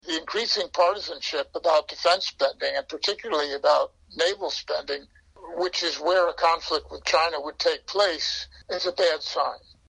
美国前海军部副次长克罗普西(Seth Cropsey)说国会两党政治分歧伤害美军与中国抗衡的能力。